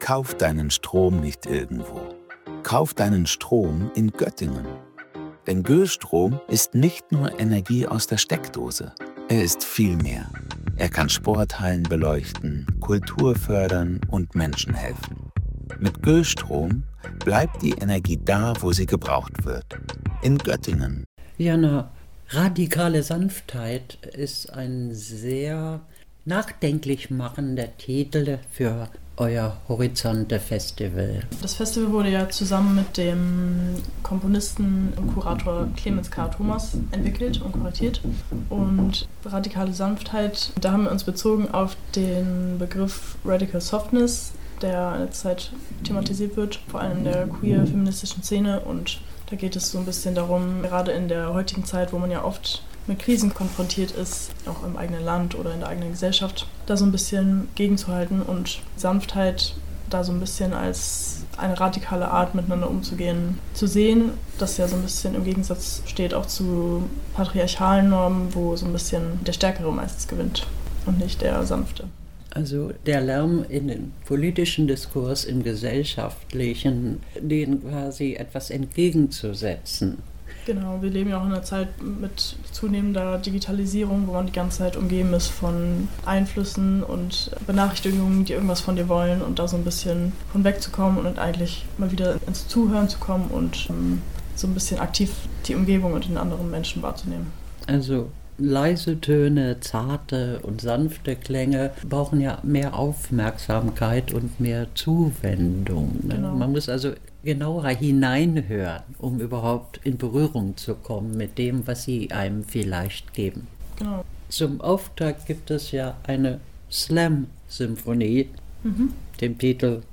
„Radikal sanft?“ - Gespräch über das „horizonte“ Festival des Göttinger Symphonieorchesters